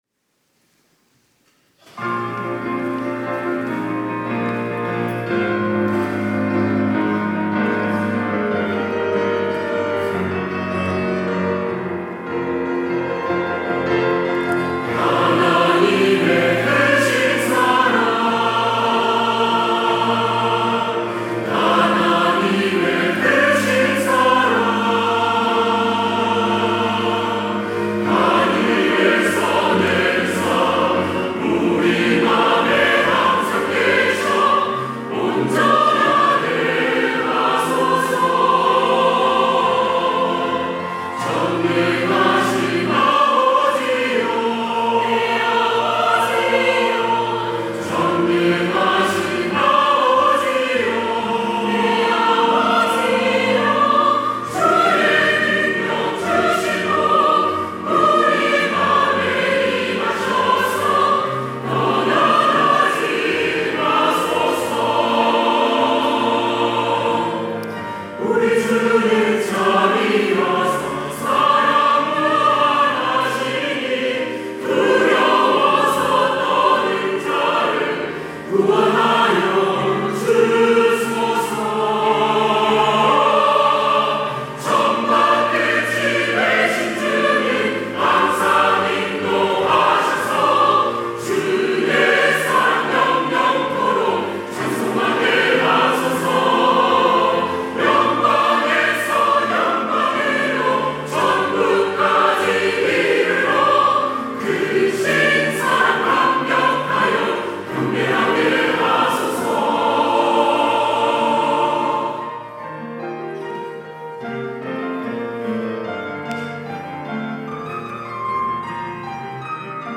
할렐루야(주일2부) - 하나님의 크신 사랑
찬양대